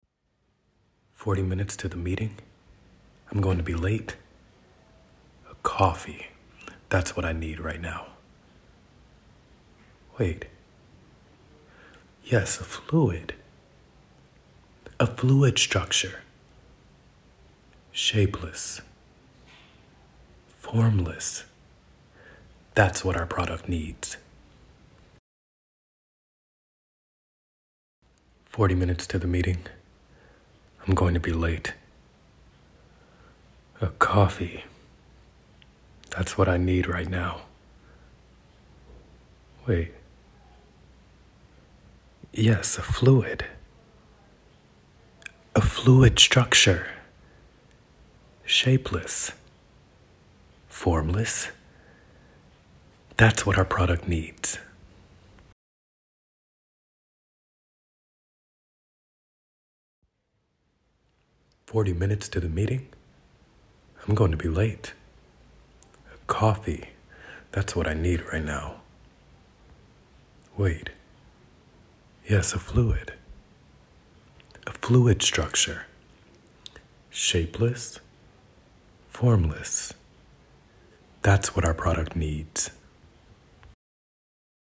VOICE ACTOR
ENGLISH - ADVERTISMENT
BRITISH ENGLISH / AMERICAN ENGLISH